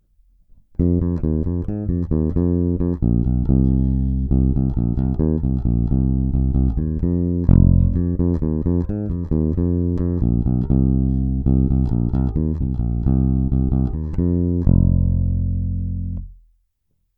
V tomto případě jsem dostal obě basy vybavené ocelovými hlazenými strunami.
Nahrávky jsou provedeny rovnou do zvukovky a dále kromě normalizace ponechány bez úprav.
Na 2EQ 93 jsem dal basy skoro naplno a výšky pocitově někde kolem střední polohy.